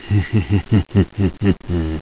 16421_Sound_(SINISTER).mp3